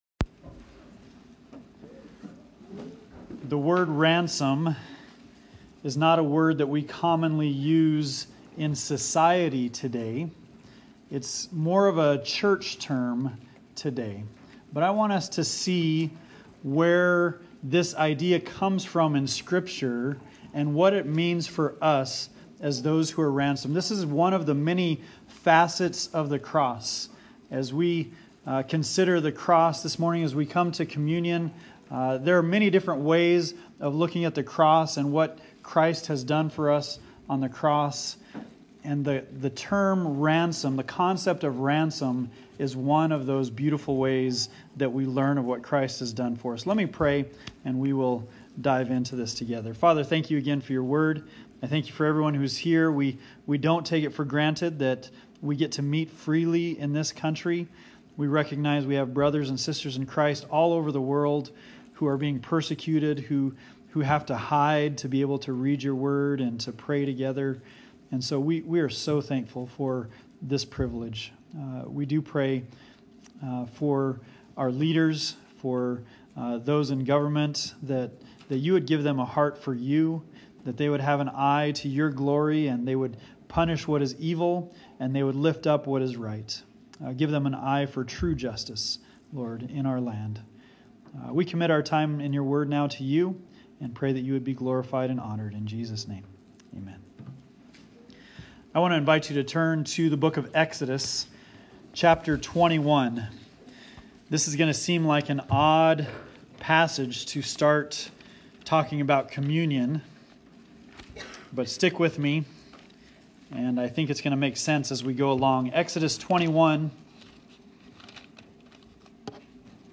Communion Meditation: Ransom